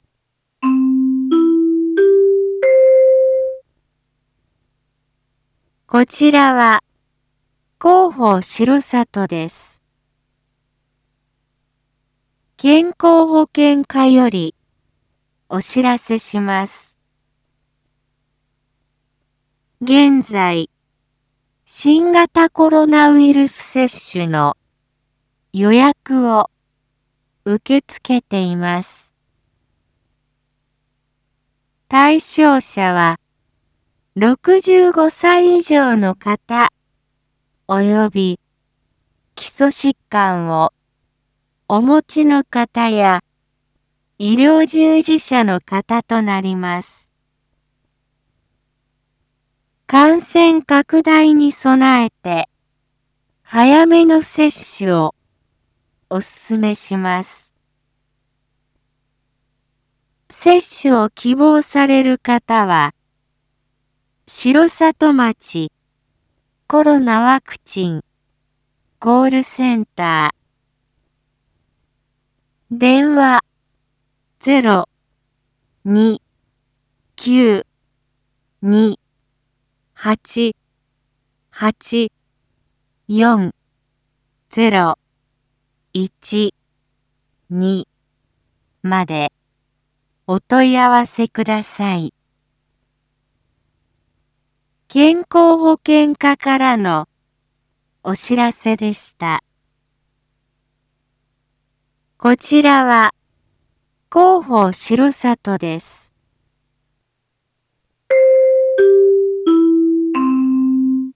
Back Home 一般放送情報 音声放送 再生 一般放送情報 登録日時：2023-06-22 07:01:52 タイトル：新型コロナウイルスワクチン接種予約について インフォメーション：こちらは、広報しろさとです。